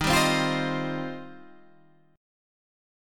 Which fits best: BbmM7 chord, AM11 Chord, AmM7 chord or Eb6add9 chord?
Eb6add9 chord